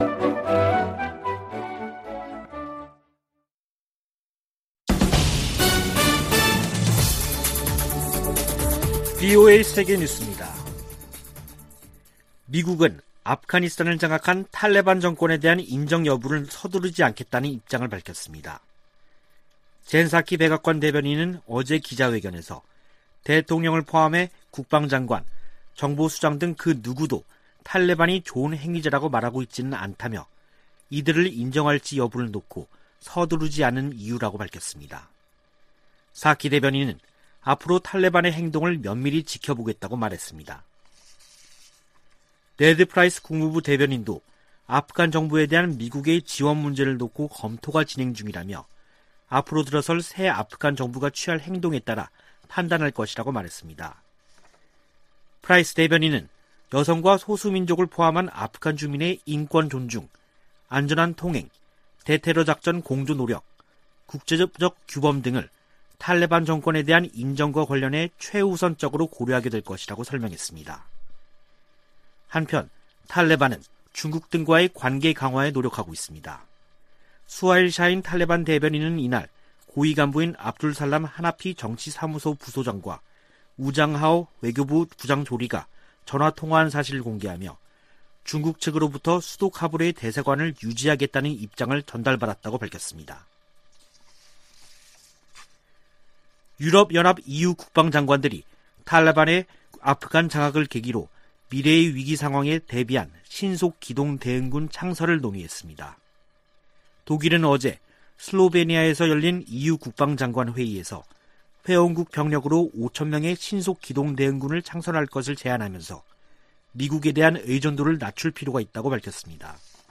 VOA 한국어 간판 뉴스 프로그램 '뉴스 투데이', 3부 방송입니다. 북한이 열병식을 준비하는 것으로 추정되는 모습이 포착됐습니다. 미국의 전직 관리들은 국무부의 북한 여행금지 연장 조치를 지지한다고 밝혔습니다. 주한미군 규모를 현 수준으로 유지해야 한다는 내용이 포함된 미국의 2022회계연도 국방수권법안이 하원 군사위원회에서 의결됐습니다.